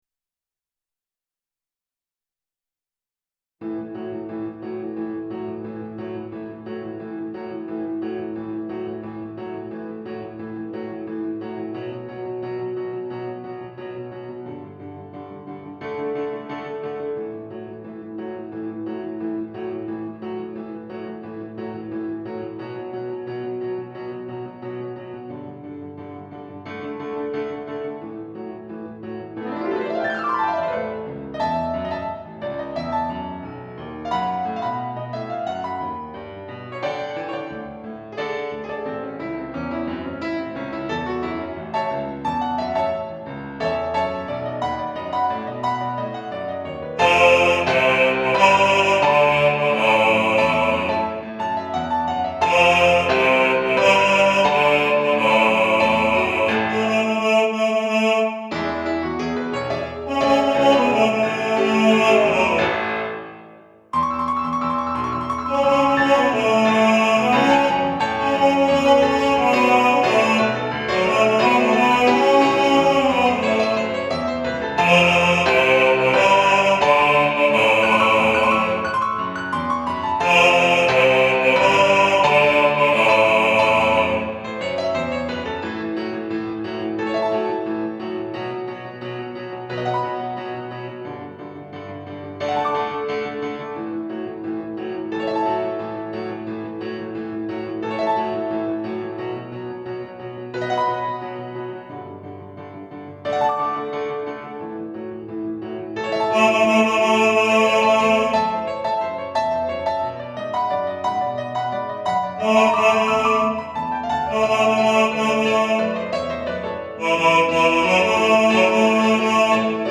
Time Warp Bass | Ipswich Hospital Community Choir